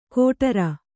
♪ kōṭara